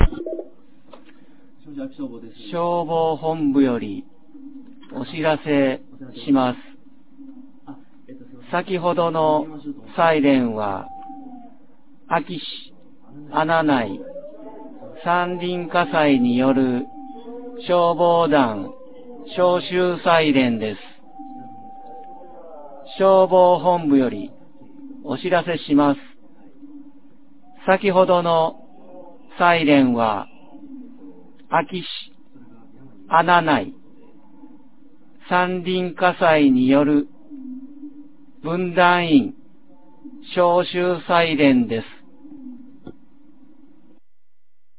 2024年01月11日 21時38分に、安芸市よりへ放送がありました。